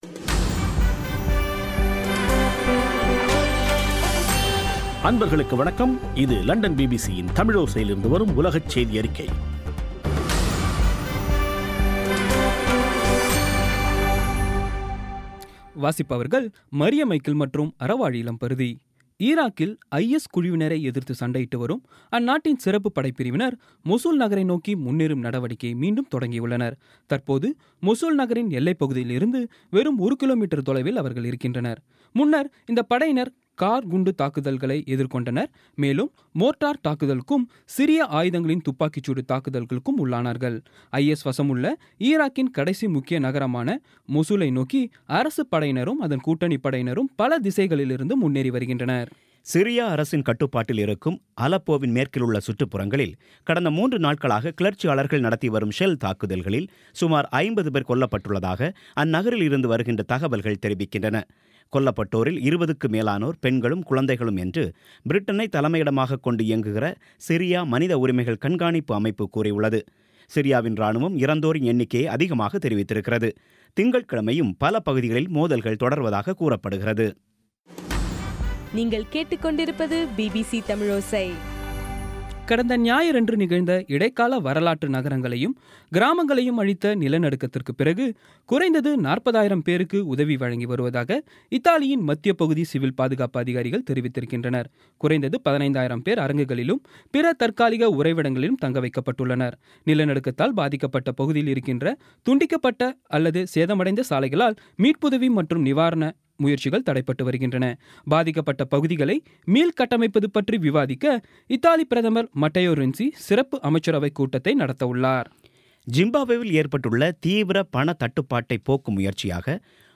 இன்றைய (அக்டோபர் 31ம் தேதி ) பிபிசி தமிழோசை செய்தியறிக்கை